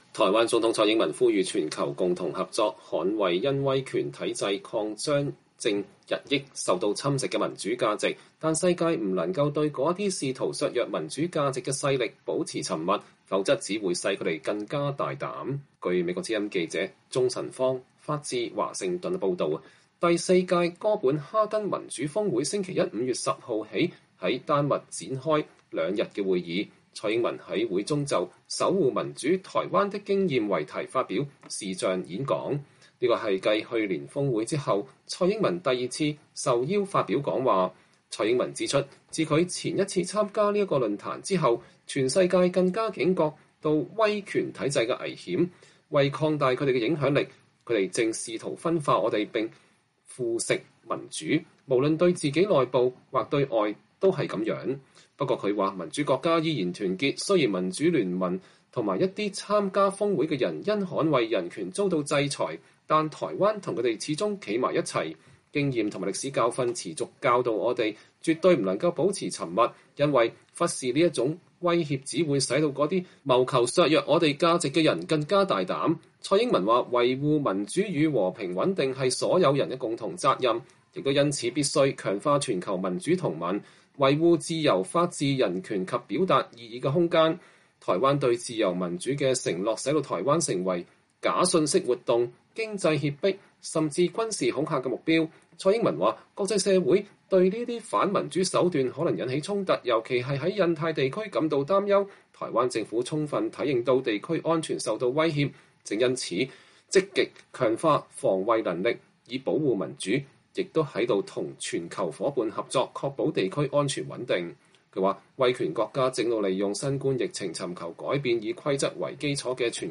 台灣總統蔡英文2021年5月10日對哥本哈根民主峰會發表視頻演說。